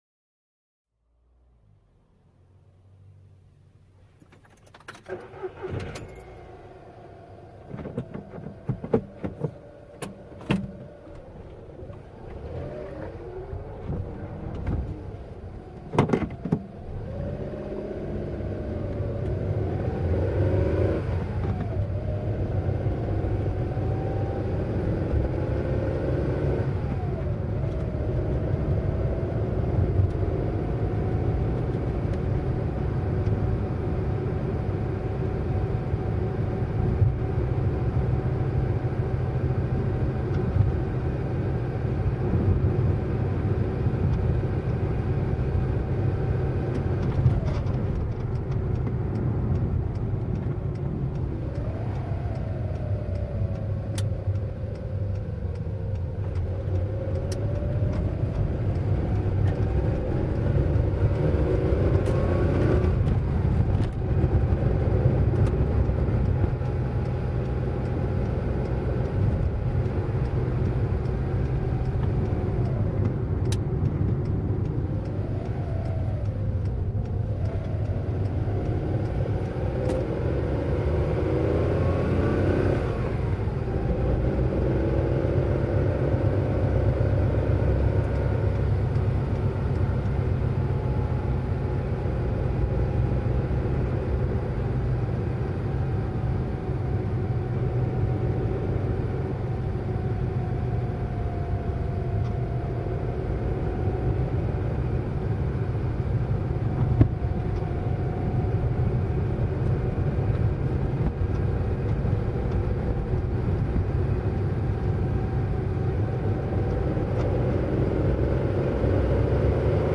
دانلود آهنگ رانندگی در ماشین 2 از افکت صوتی حمل و نقل
دانلود صدای رانندگی در ماشین 2 از ساعد نیوز با لینک مستقیم و کیفیت بالا